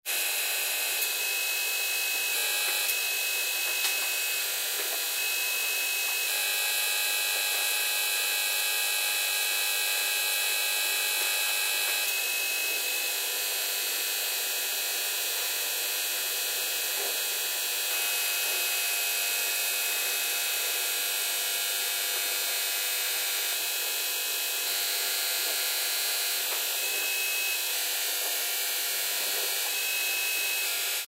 Electricidad estática de una televisión de plasma 02